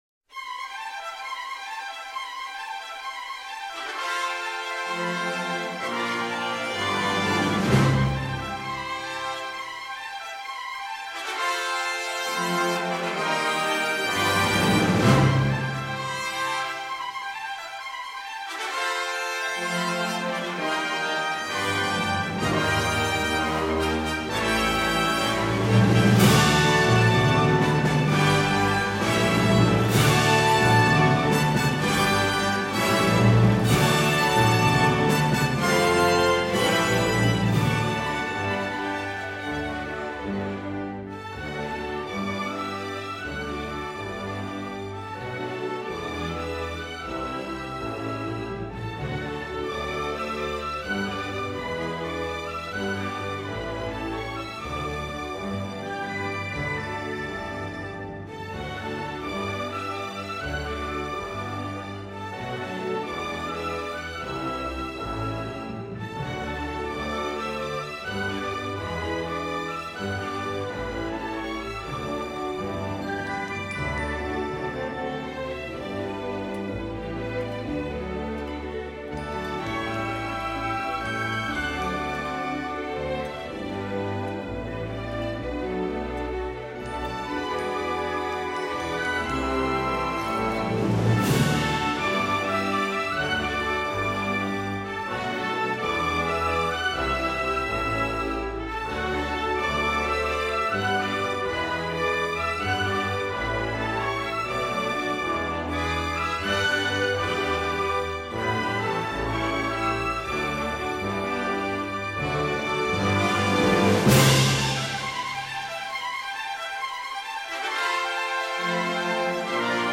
乐    器:  交响乐 　　　　　　　　　　　　　　　  　　　　　　.
本专辑收录了十多首节奏经快及动态澎湃的经典西部电影歌曲；本专